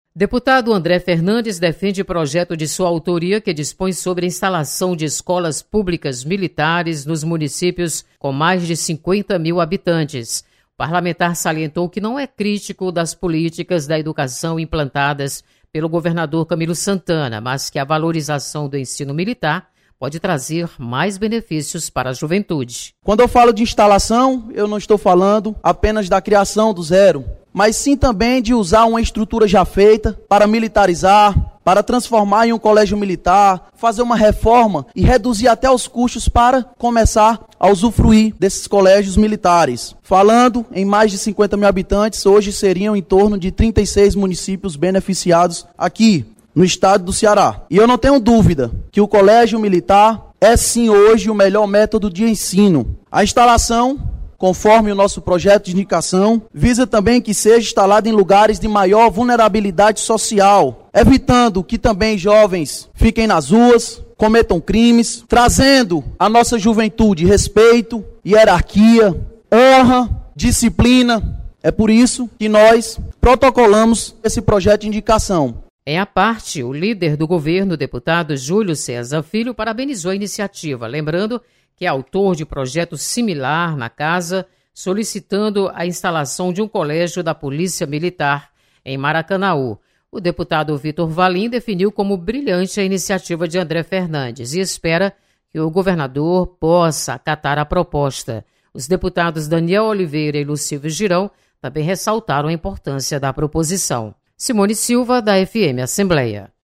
Deputado André Fernandes defende instalação de escolas militares no Ceará.